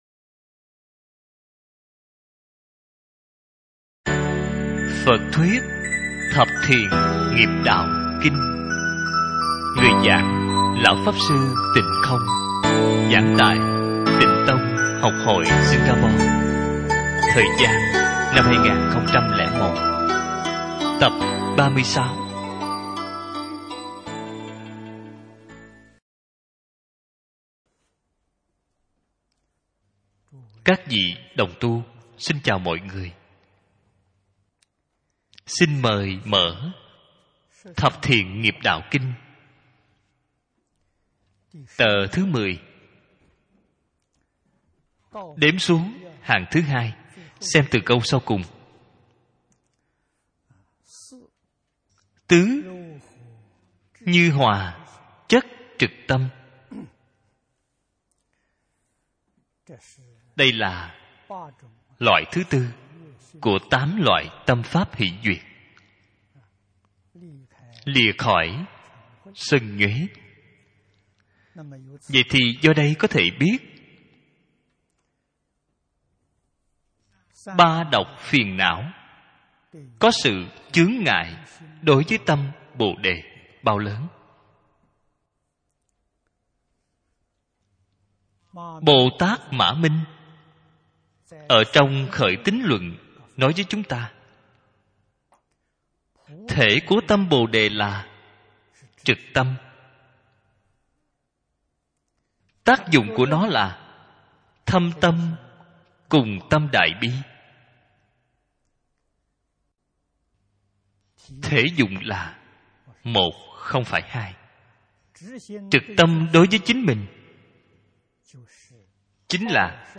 PHẬT THUYẾT THẬP THIỆN NGHIỆP ĐẠO KINH GIẢNG GIẢI